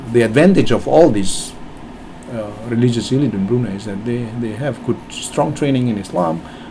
S1 = Indonesian male S2 = Malaysian female Context: S1 is talking about religious leaders in Brunei.
In fact, elite is said quite quietly and is difficult to pick up. There is no clear stress on the second syllable, so it is possible that absence of lexical stress on this word plays a part in this misunderstanding.